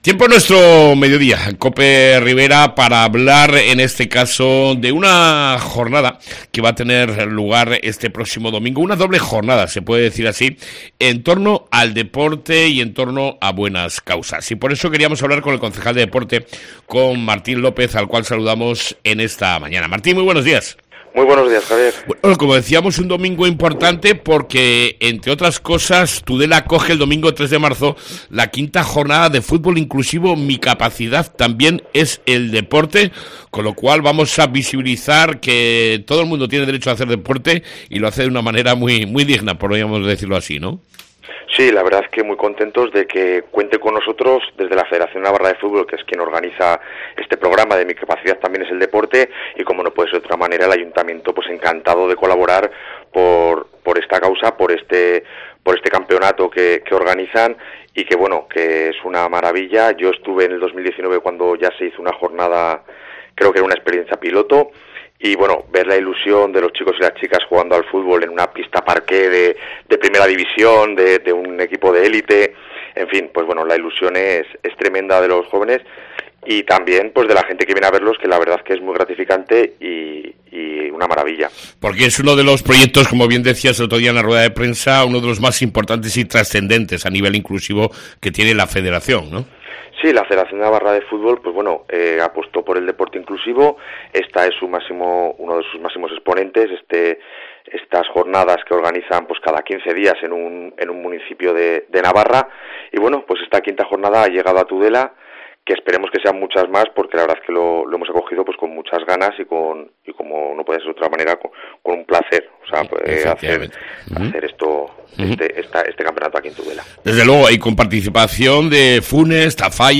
Entrevista con el Concejal Martín López (Domingo Solidario y deportivo)